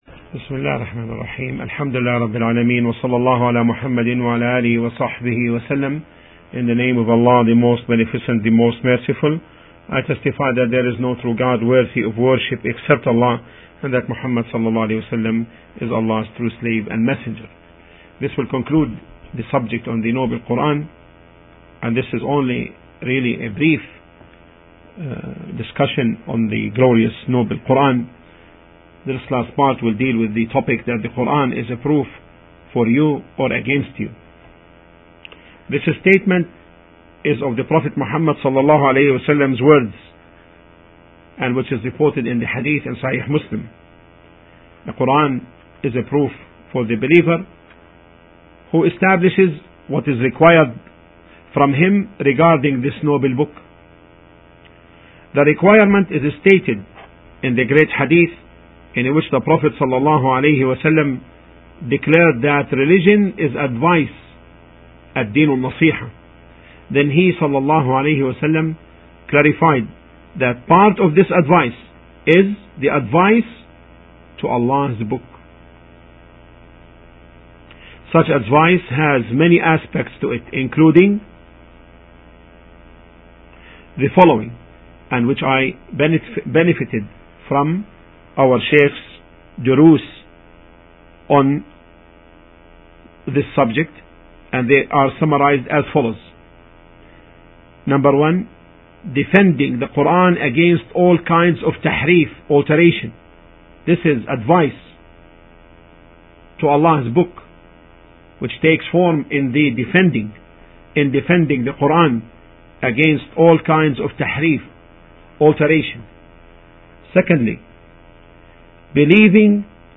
This is a lecture